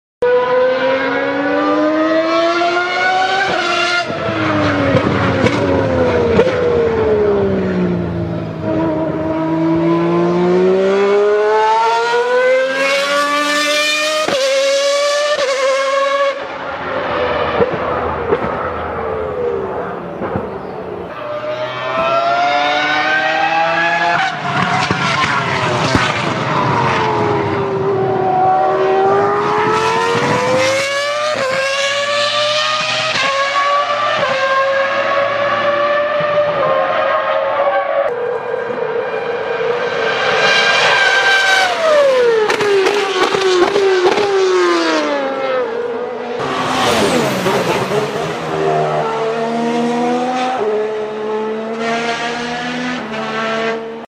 All Formula 1 V6 V12 engine sound effects free download
All Formula 1 V6-V12 engine sounds